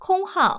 ivr-unallocated_number.wav